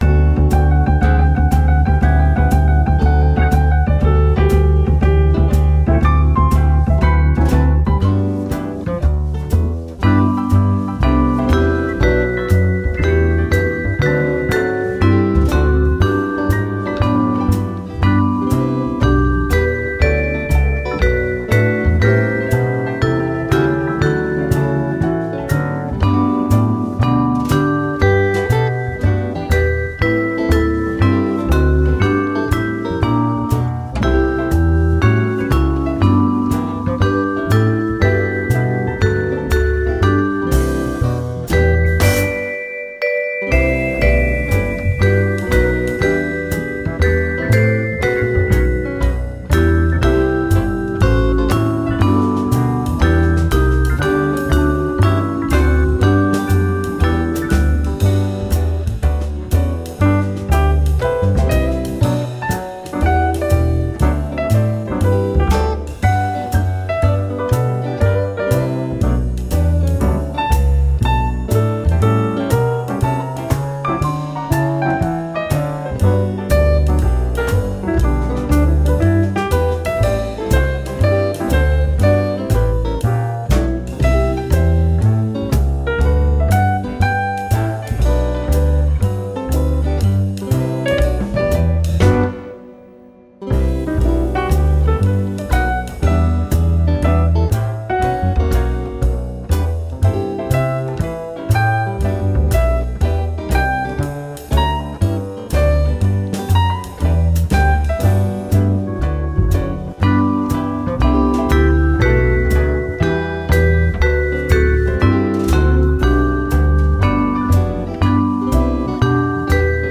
Deux versions instrumentales tirées du site de jp.w